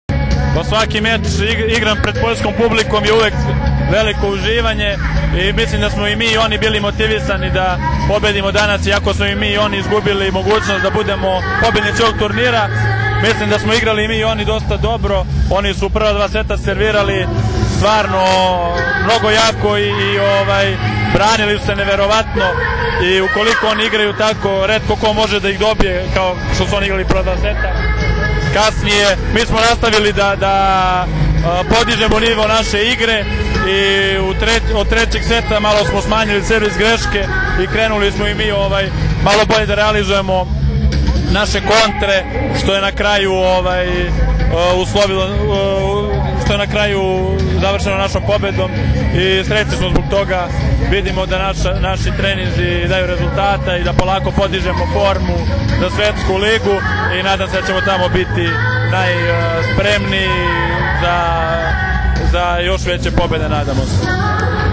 IZJAVA SREĆKA LISINCA